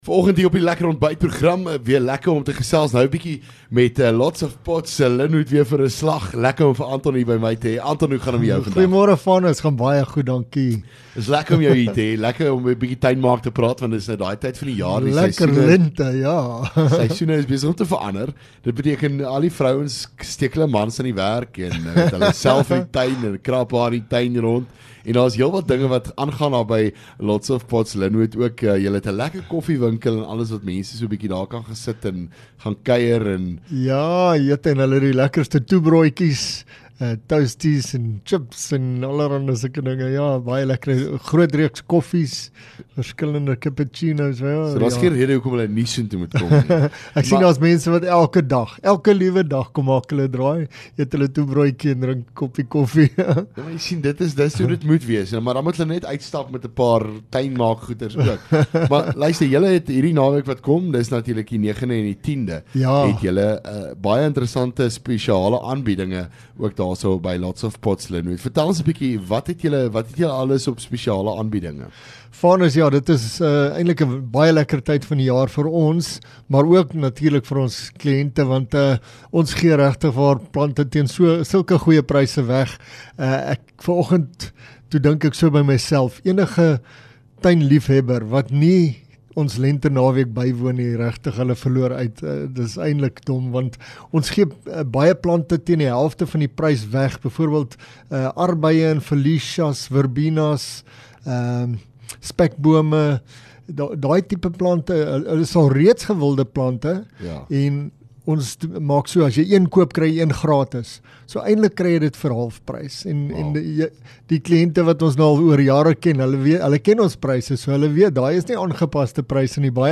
LEKKER FM | Onderhoude 5 Sep Lots Of Pots Lynwood